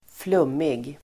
Ladda ner uttalet
flummig.mp3